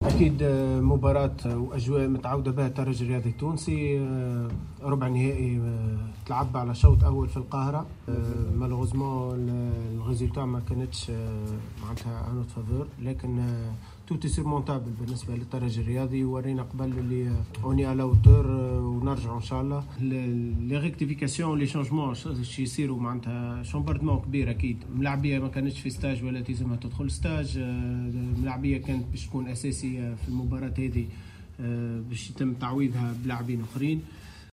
مؤتمرا صحفيا